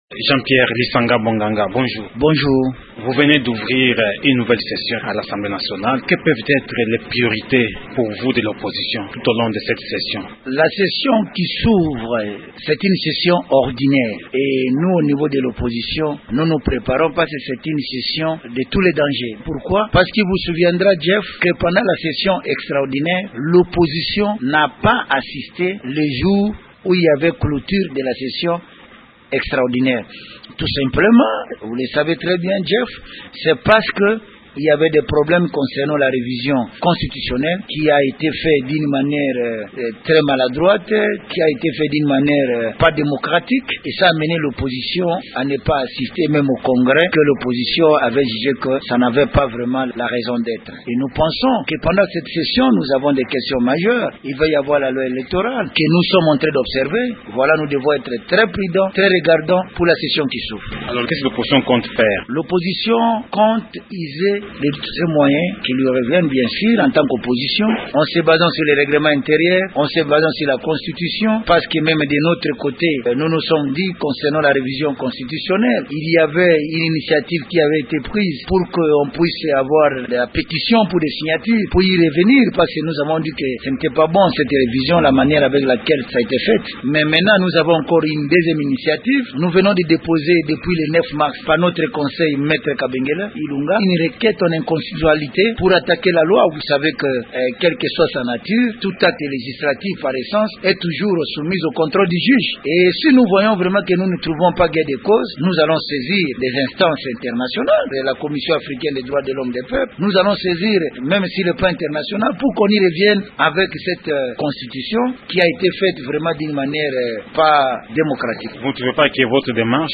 Quelles sont les revendications de Lisanga Bonganga par rapport à la constitution révisée ? Entretien